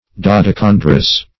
Search Result for " dodecandrous" : The Collaborative International Dictionary of English v.0.48: Dodecandrian \Do`de*can"dri*an\, Dodecandrous \Do`de*can"drous\, a. (Bot.)
dodecandrous.mp3